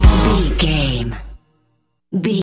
Aeolian/Minor
A♭
Fast
drum machine
synthesiser